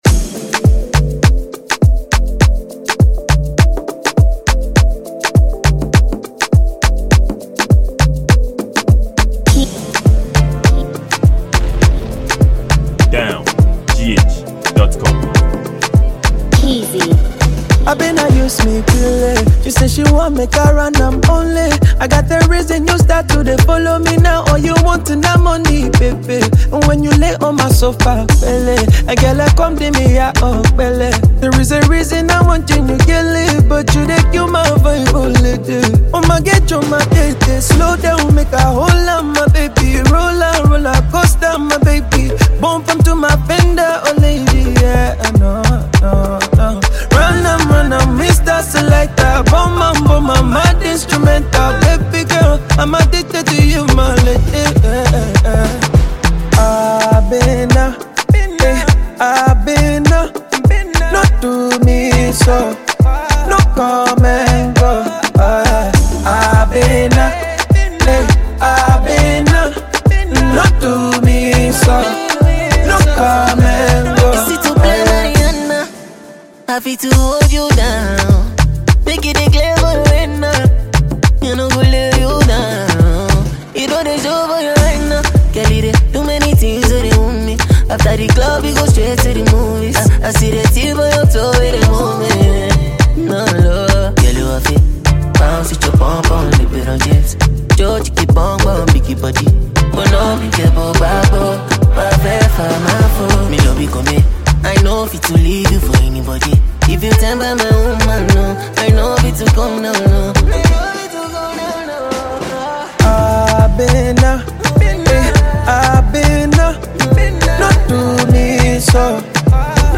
Talented afrobeat Ghanaian singer and songwriter